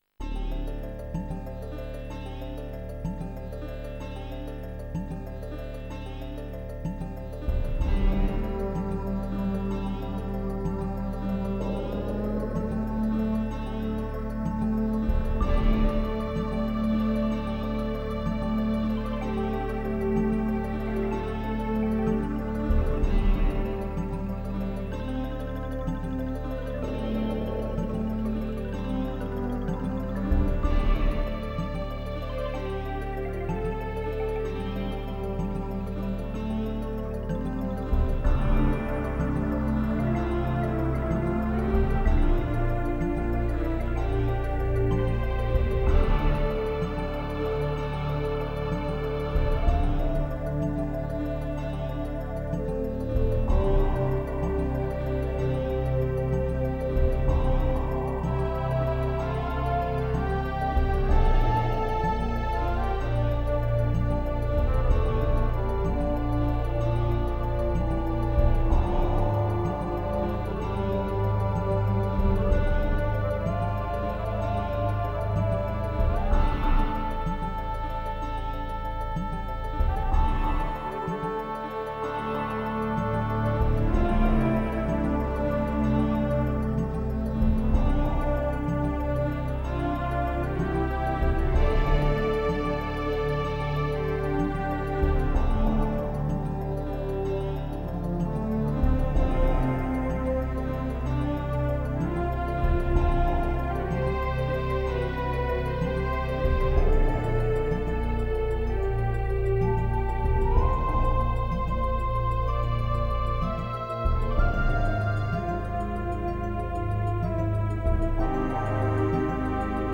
Not 100% perfect quality but good enough!!!:
joint stereo